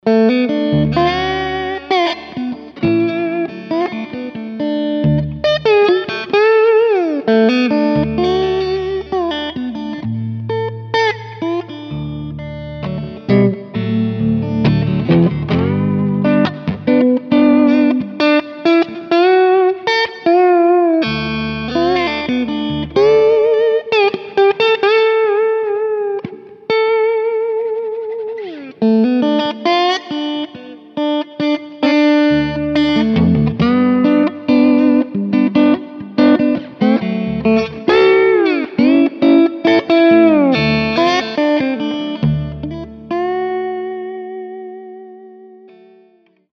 Gold Foil Slide Demo
Gold-Foils-Demo-2.mp3